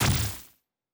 Basic Attack Hit.wav